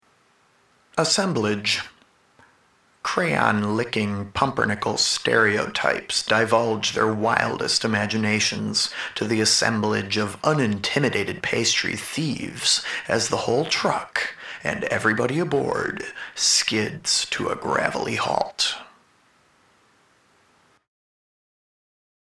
Here’s the first of a series of revisited poems that are read without commentary, very minimally.